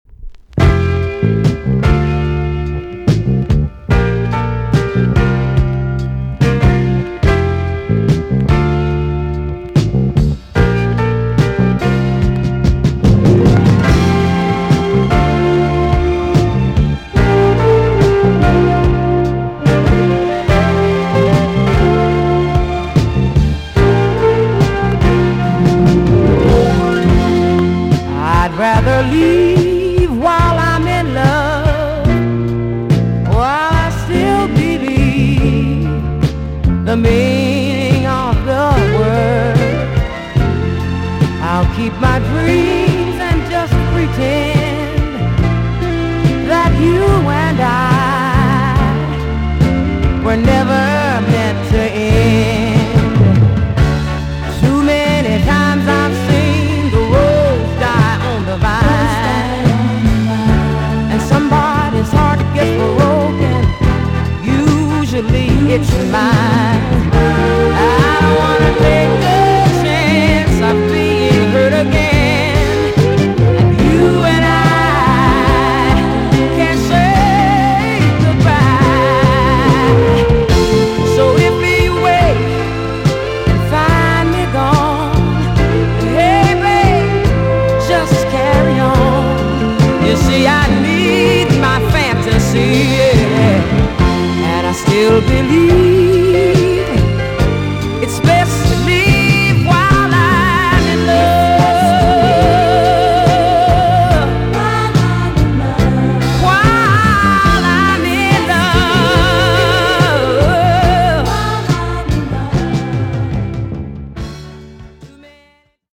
TOP >JAMAICAN SOUL & etc
EX- 音はキレイです。